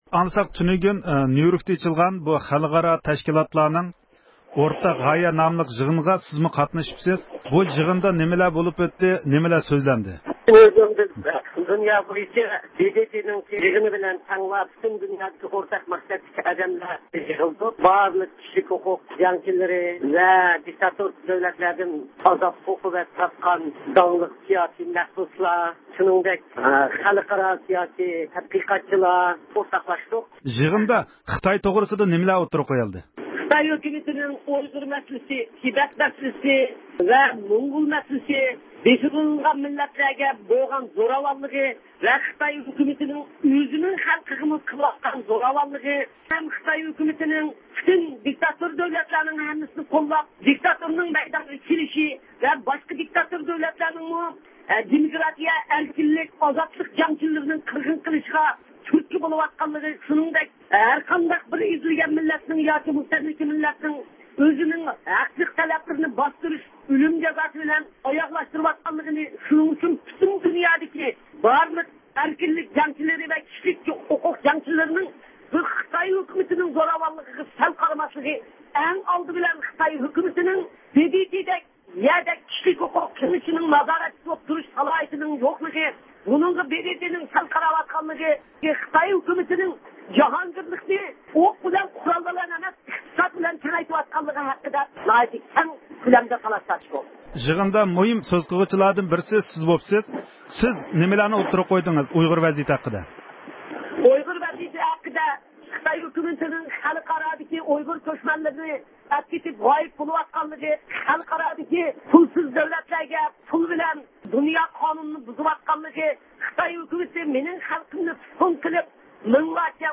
ۋاشىنگتوندىكى ئايرودرومغا قاراپ كېتىۋاتقان رابىيە خانىم مېترو بېكىتىدە مۇخبىرىمىز زىيارىتىنى قوبۇل قىلىپ پەيشەنبە ۋە جۈمە كۈنلۈك پائالىيەتلىرى ھەققىدە مەلۇمات بەردى.